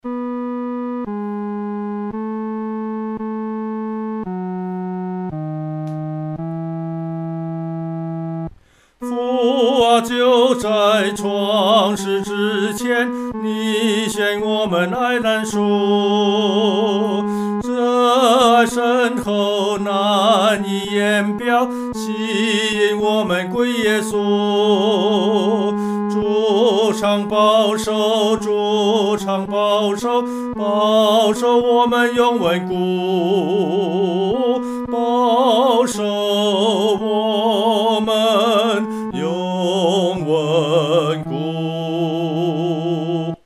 独唱（第三声）
赞父奇爱-独唱（第三声）.mp3